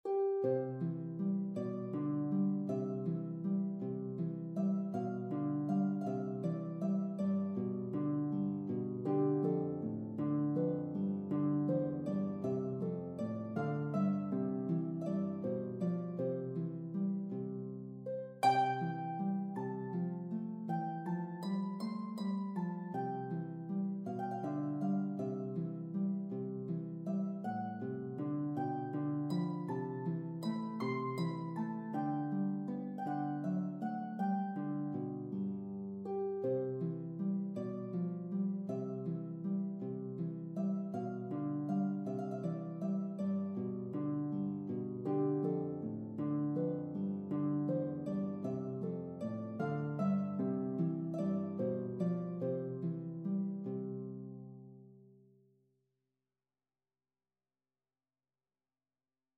Harp version
6/8 (View more 6/8 Music)
C major (Sounding Pitch) (View more C major Music for Harp )
Andante